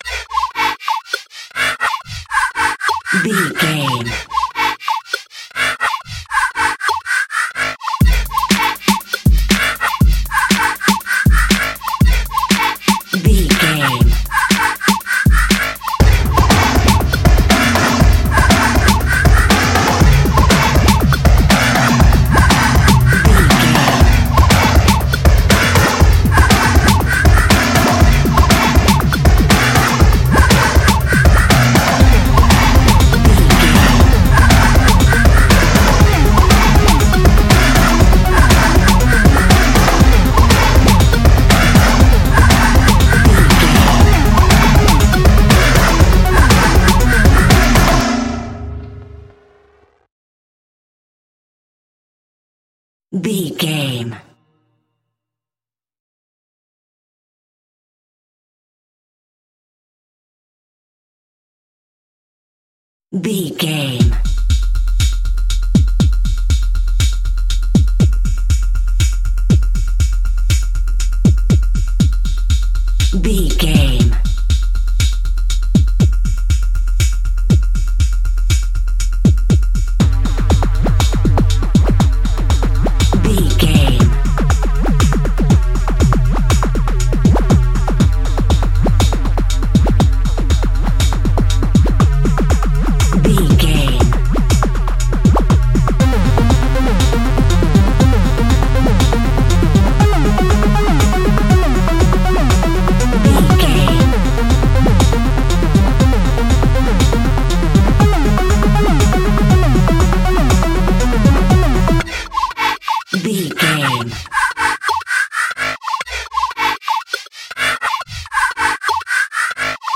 Atonal
intense
energetic
driving
aggressive
dark
piano
synthesiser
drum machine
breakbeat
synth bass